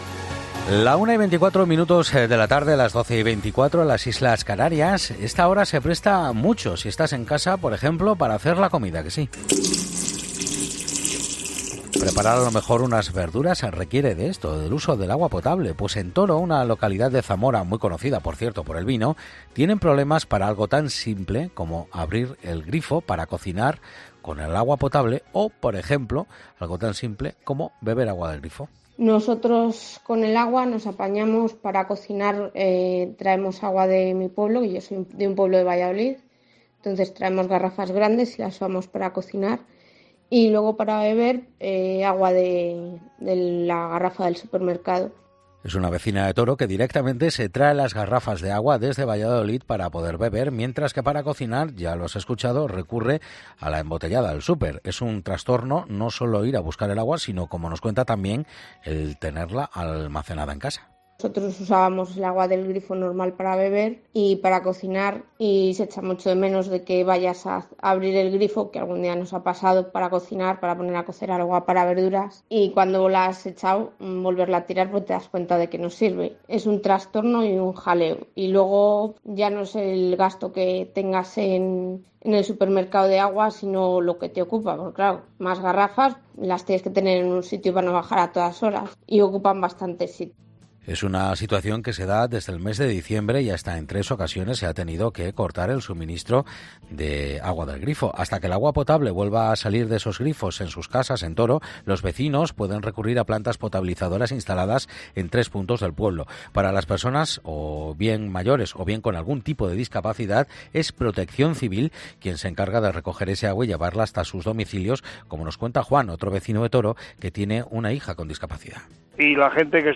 Tomás del Bien, alcalde de Toro, explica en 'Mediodía COPE' el porqué el agua de la localidad no es potable